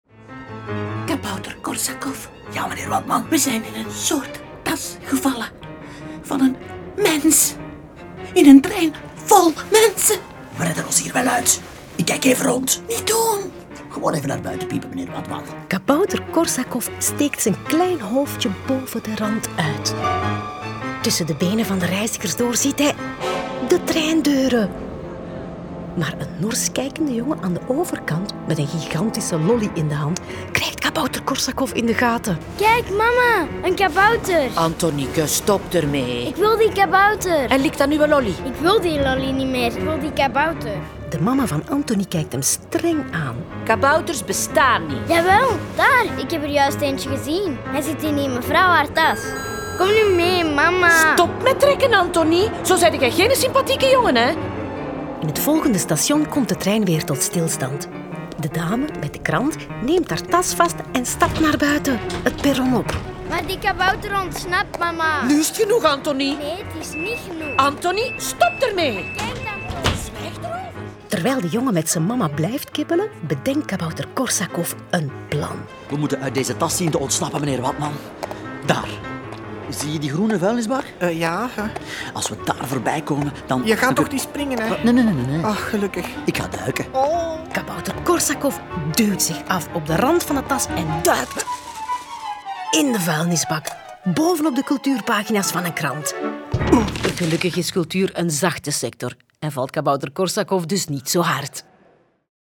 Kabouter Korsakov is een reeks luisterverhalen voor avonturiers vanaf 4 jaar.